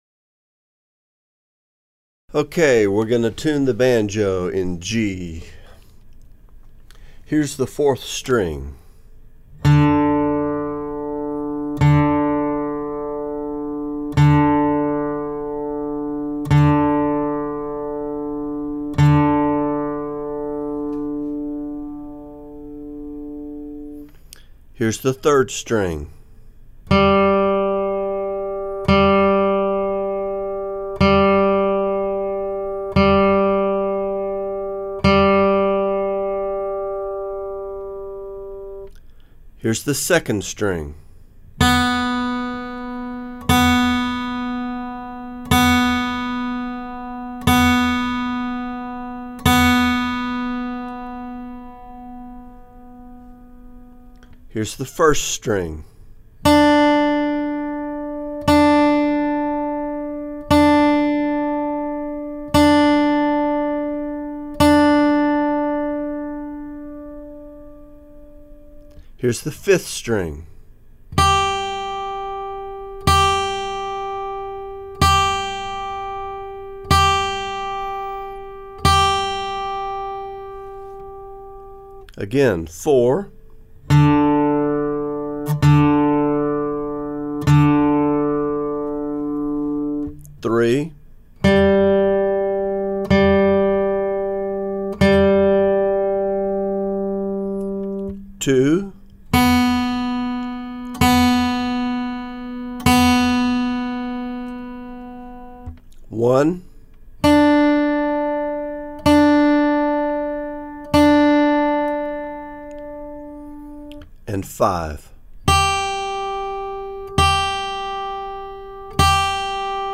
tuning in G
tuning in G.mp3